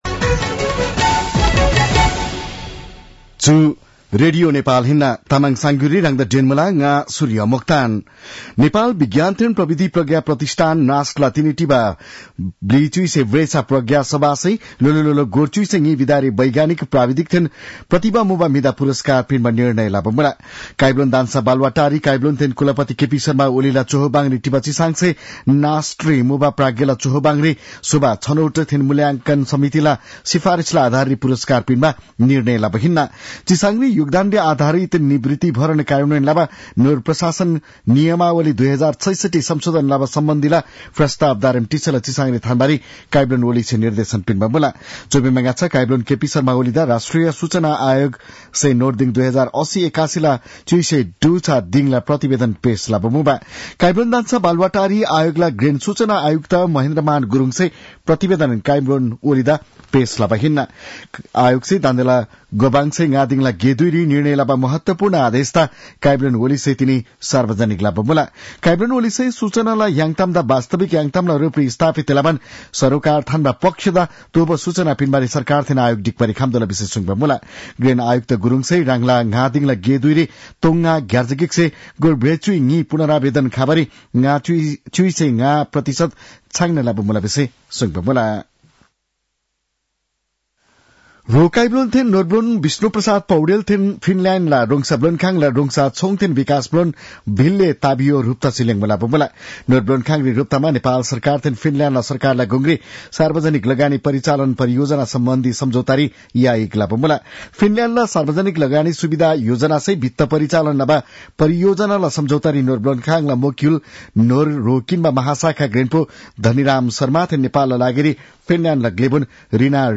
तामाङ भाषाको समाचार : १४ मंसिर , २०८१
Tamang-news-8-13.mp3